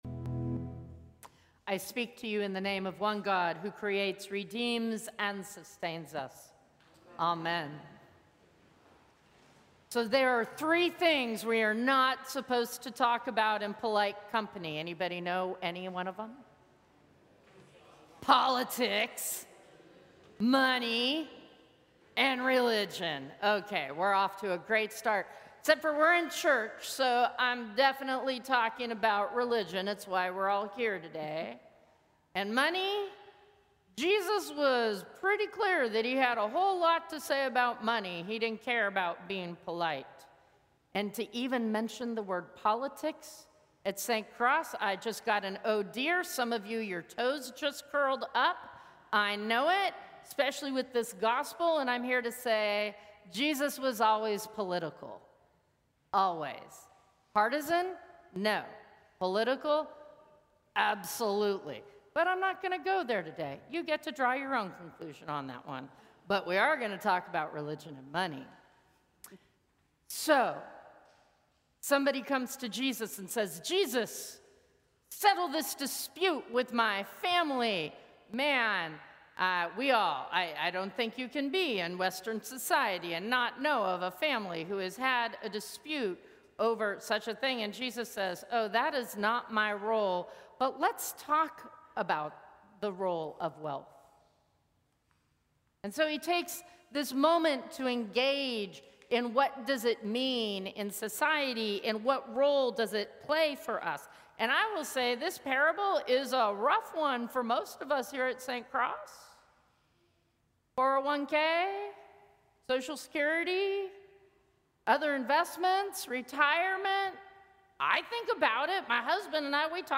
Sermons from St. Cross Episcopal Church Eighth Sunday after Pentecost Aug 03 2025 | 00:11:54 1x 00:00 / 00:11:54 Subscribe Share Apple Podcasts Spotify Overcast RSS Feed Share Link Embed